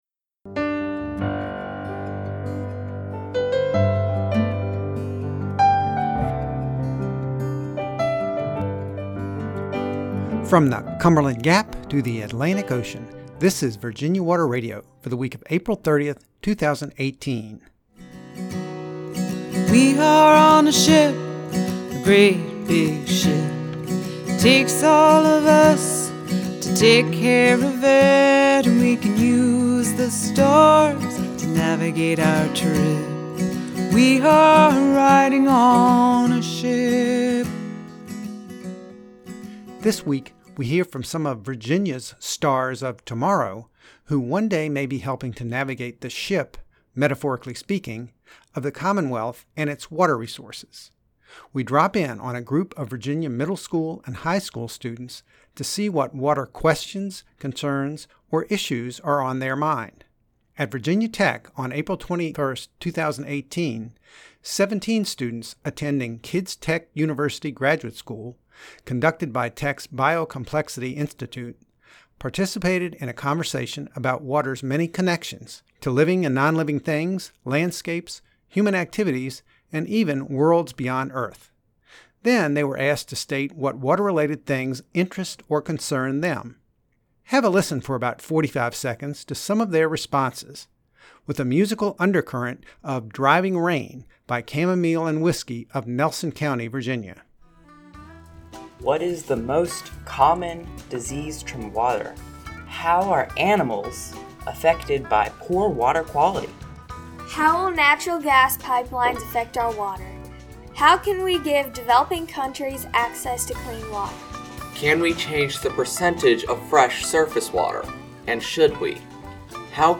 The voices heard in this episode were recorded on April 21, 2018, in Blacksburg, Va., at a session of Kids Tech University—Graduate School, conducted by Virginia Tech’s Biocomplexity Institute.